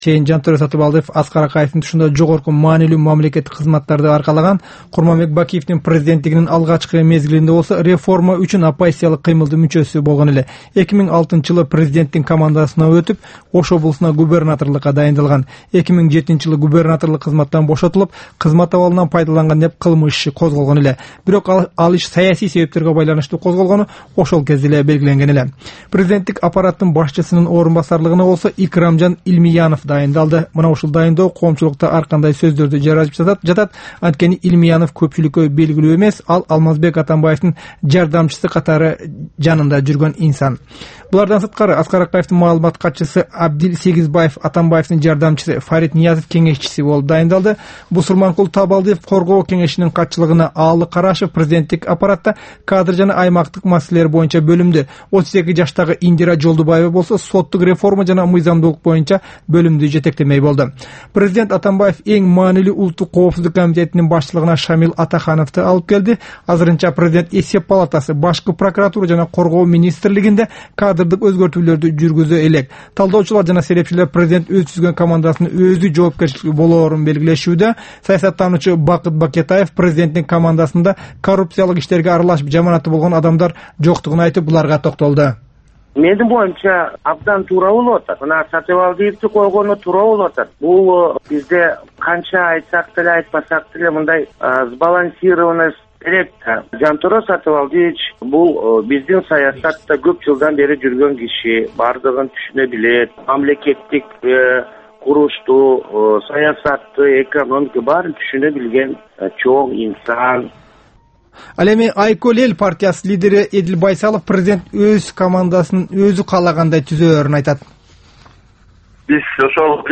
Бул түшкү үналгы берүү жергиликтүү жана эл аралык кабарлар, ар кыл орчун окуялар тууралуу репортаж, маек, талкуу, кыска баян жана башка оперативдүү берүүлөрдөн турат. "Азаттык үналгысынын" бул түш жаңы оогон учурдагы берүүсү Бишкек убакыты боюнча саат 13:00төн 13:30га чейин обого чыгарылат.